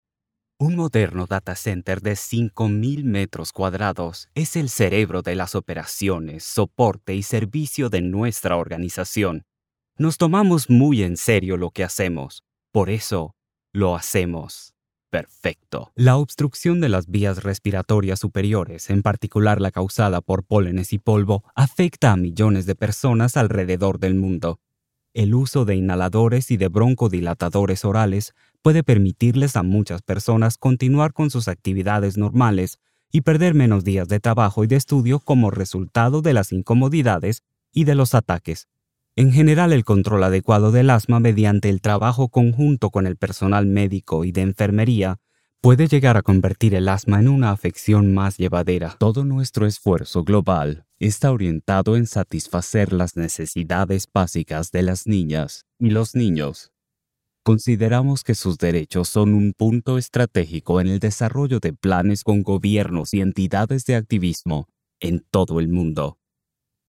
Trilingual voiceover artist, ACL, AMELOC, English, Standard Latin American Spanish, German, locutor, voz
Sprechprobe: Industrie (Muttersprache):